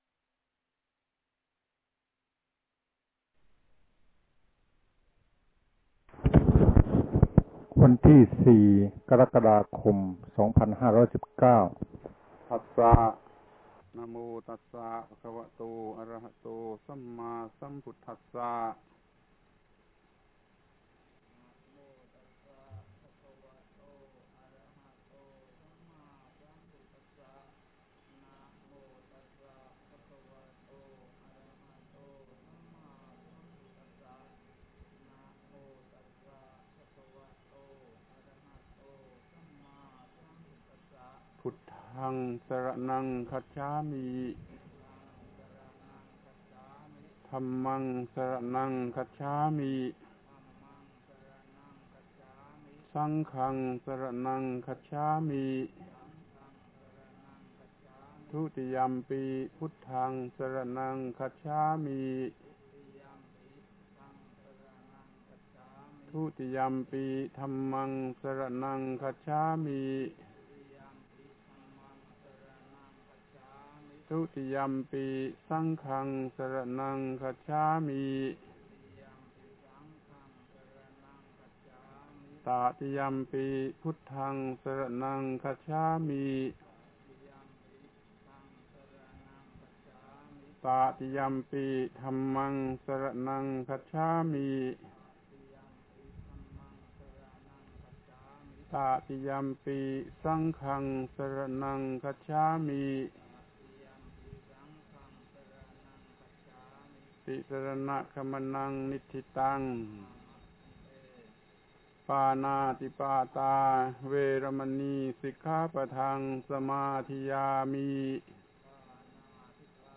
โอวาทอุปสมบท